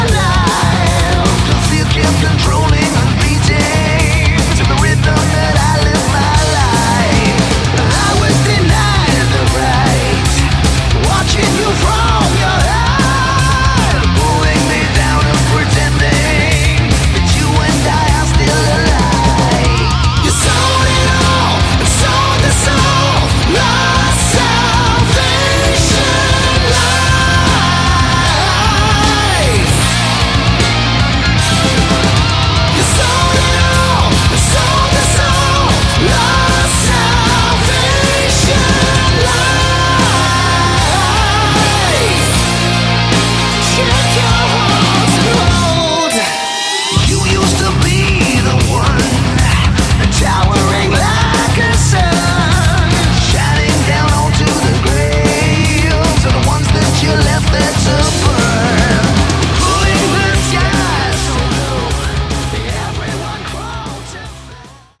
Category: Modern Hard Rock
vocals
bass
drums
guitars